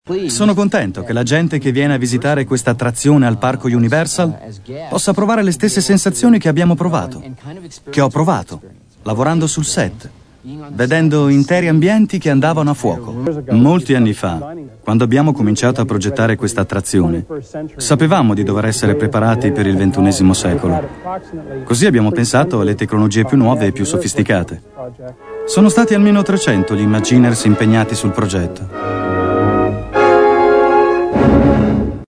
LA MACCHINA DEL TEMPO (RETE 4 - VOICE OVER)